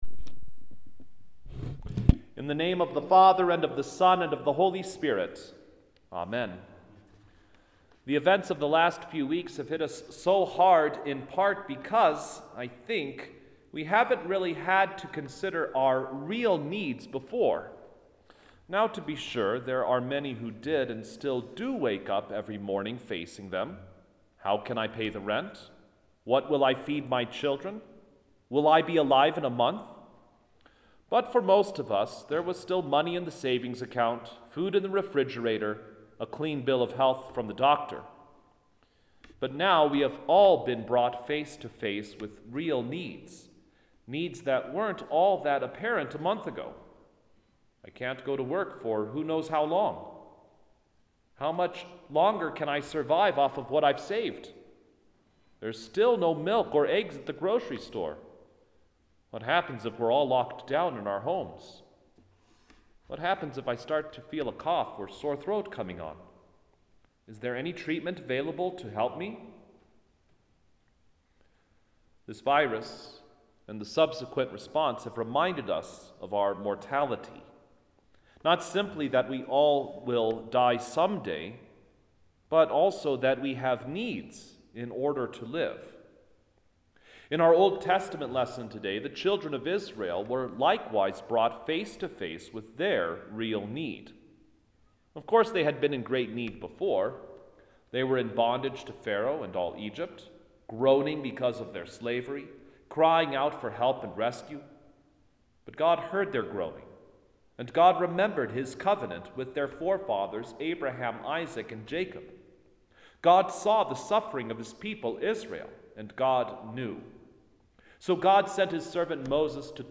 The Fourth Sunday in Lent – Matins